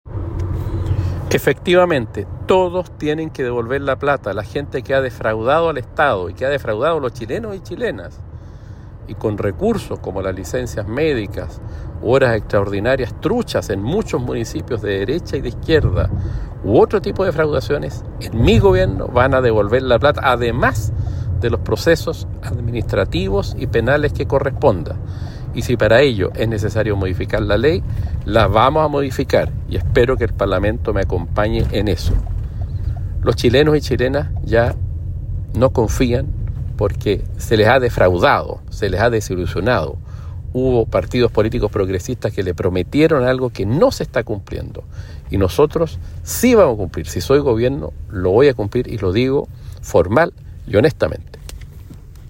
Con firmeza y tono crítico, el candidato a la primaria presidencial de la centroizquierda, Cristián Mulet, se refirió este martes a los casos de corrupción que han salpicado al sector público, incluyendo el uso irregular de licencias médicas.